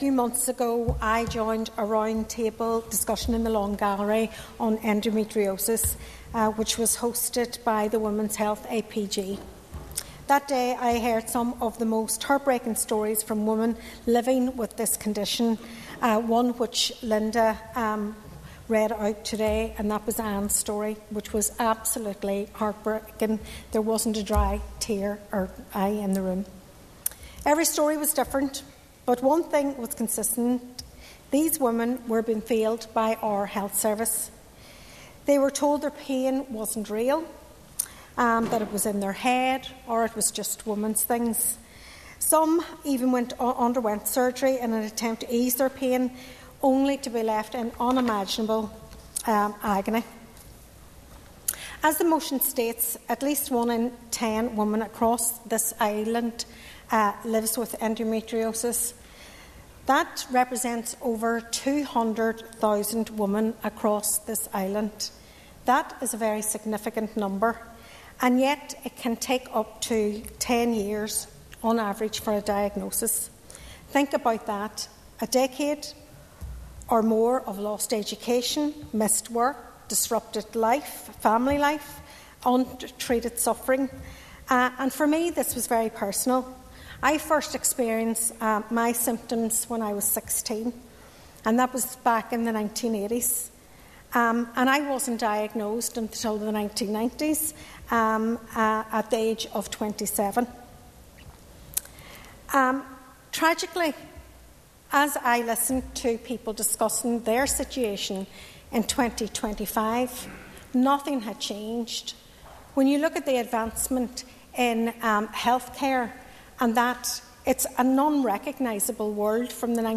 A motion came before the Northern Ireland Assembly this week calling for the establishment of specialist services to diagnose and treat the condition.
She recalled her own personal journey with endometriosis: